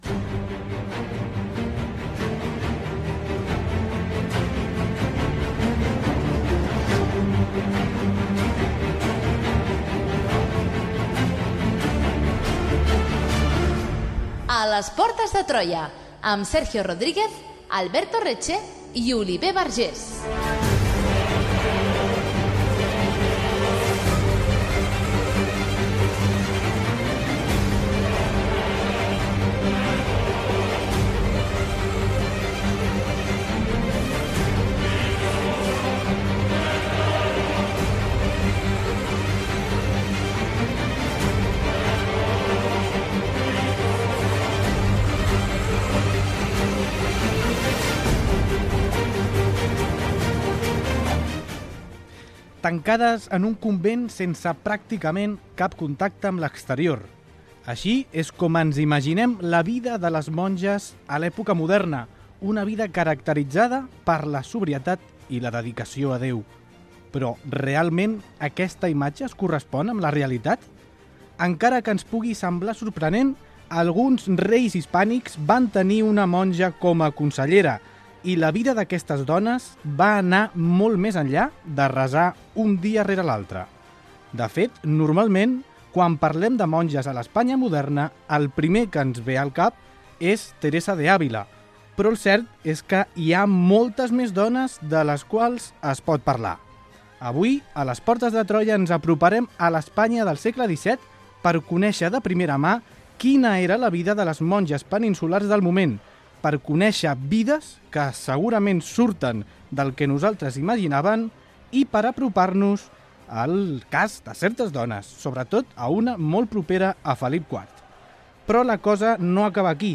Careta i presentació del programa dedicat a les monges del segle XVII a la Península Ibèrica
Divulgació
FM